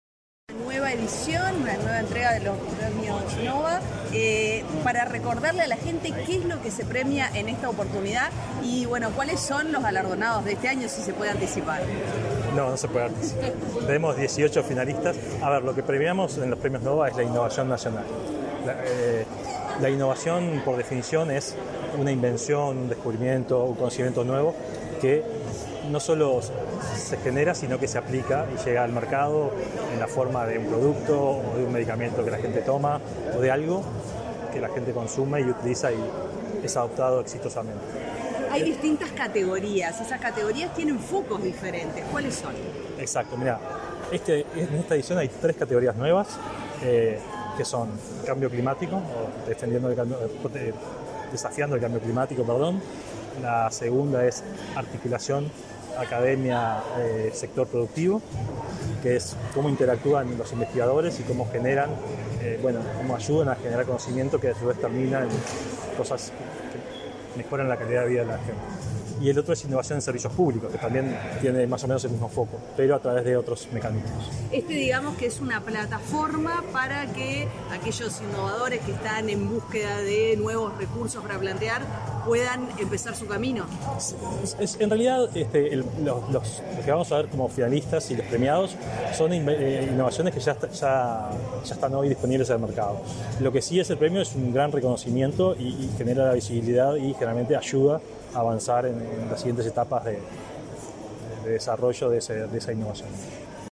Declaraciones a la prensa del presidente de la ANII, Flavio Caiafa
En la oportunidad, el presidente de la institución, Flavio Caiafa, realizó declaraciones a la prensa.